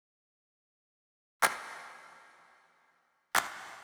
32 Clap.wav